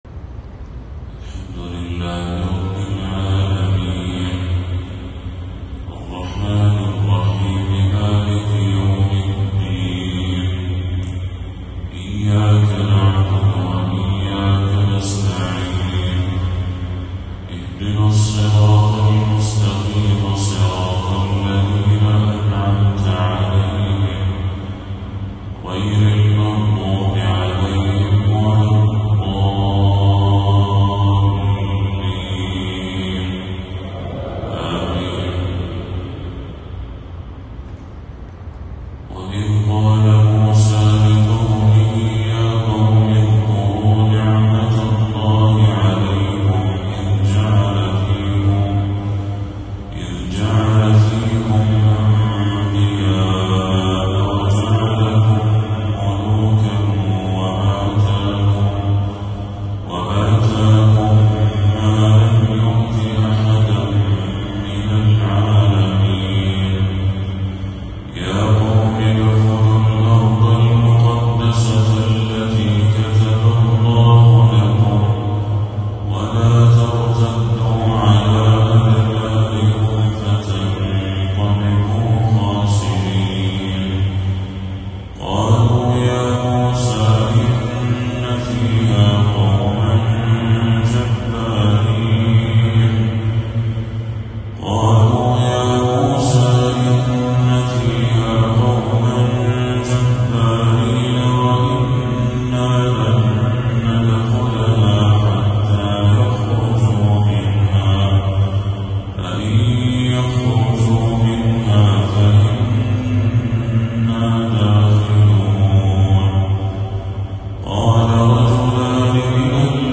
تلاوة ندية من سورة المائدة للشيخ بدر التركي | فجر 13 صفر 1446هـ > 1446هـ > تلاوات الشيخ بدر التركي > المزيد - تلاوات الحرمين